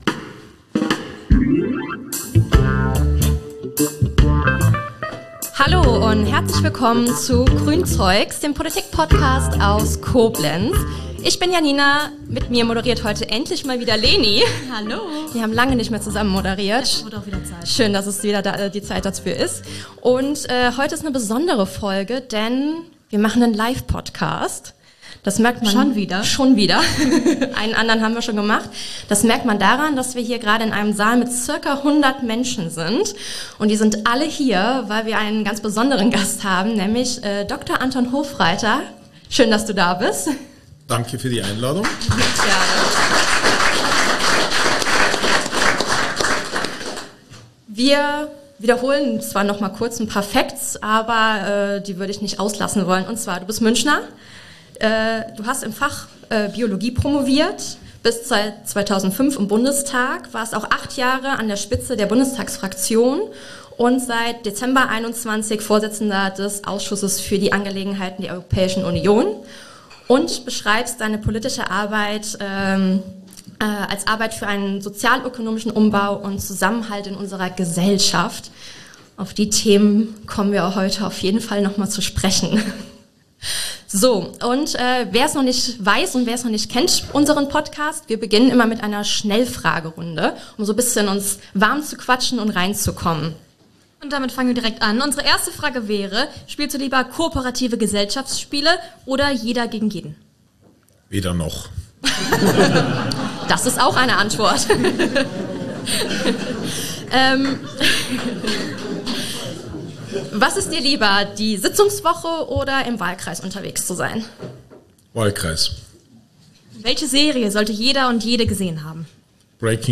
Livepodcast mit Dr. Anton Hofreiter ~ Grünzeugs Podcast
Zudem gab es zahlreiche Fragen aus dem Publikum, dem Toni Rede und Antwort stand.